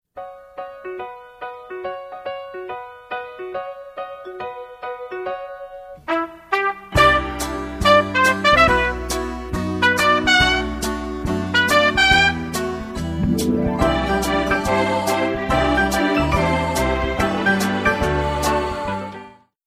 Джазовая мелодия.